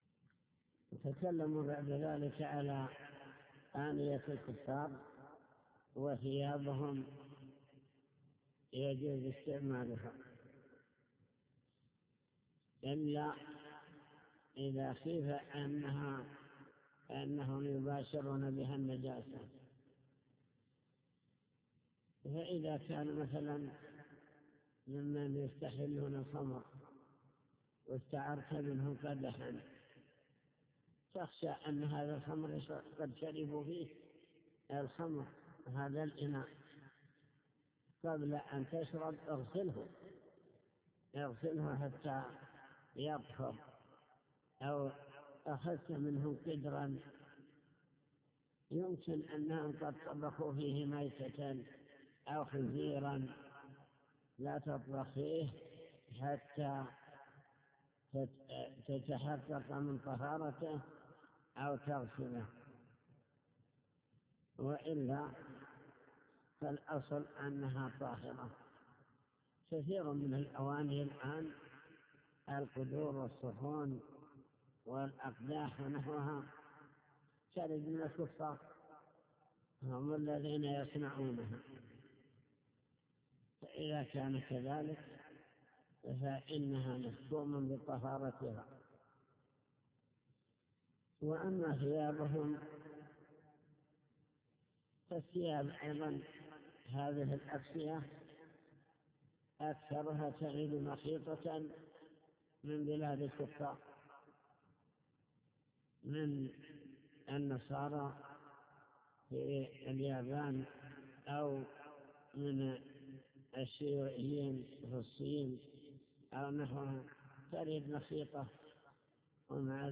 المكتبة الصوتية  تسجيلات - كتب  شرح كتاب دليل الطالب لنيل المطالب كتاب الطهارة باب الآنية